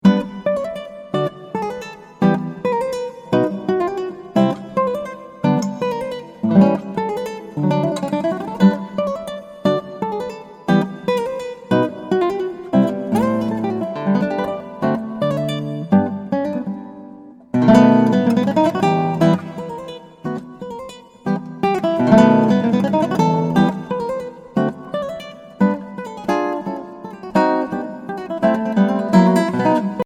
Voicing: Guitar Collection